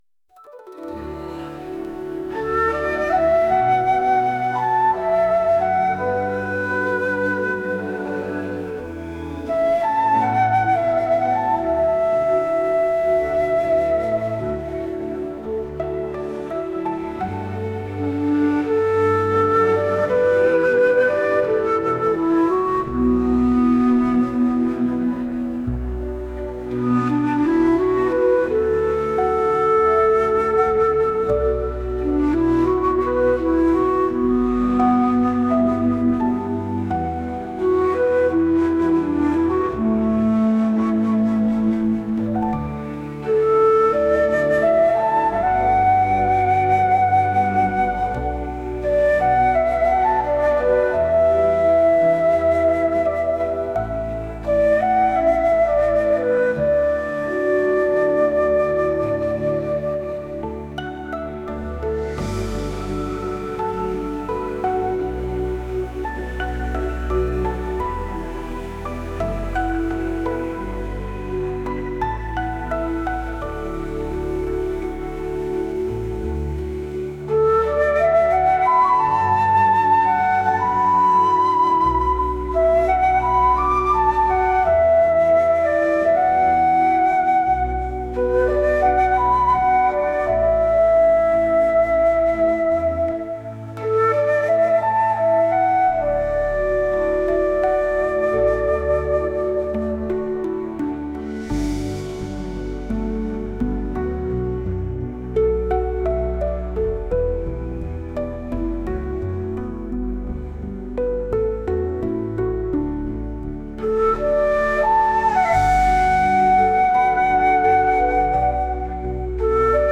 日本の森の中にある神聖な空気をまとった場所のイメージの音楽です。